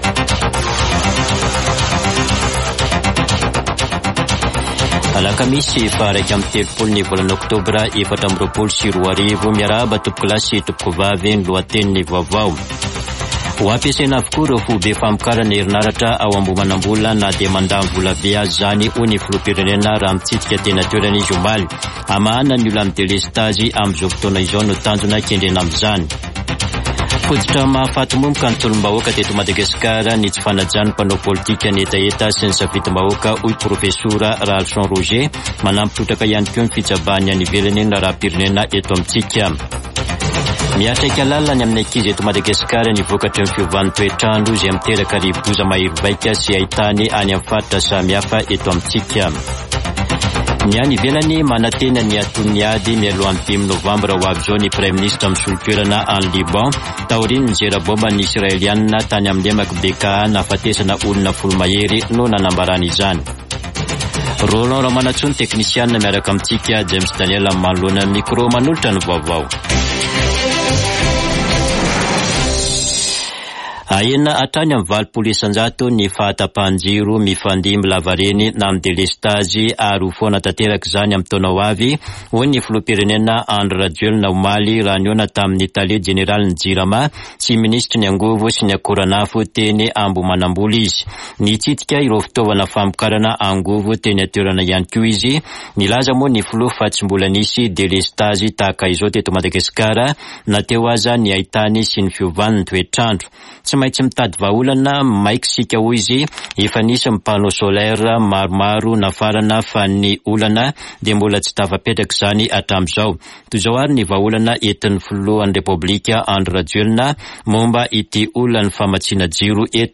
[Vaovao maraina] Alakamisy 31 Oktobra 2024